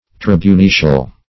Tribunician \Trib`u*ni"cian\, Tribunitial \Trib`u*ni"tial\,